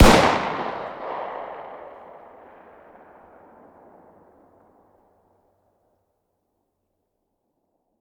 fire-dist-40sw-pistol-ext-03.ogg